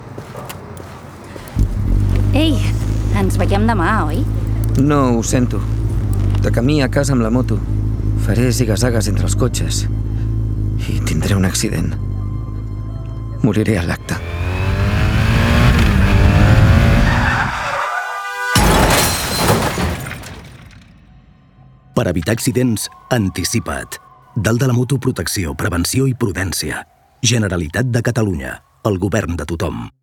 Per evitar accidents anticipa't falca 2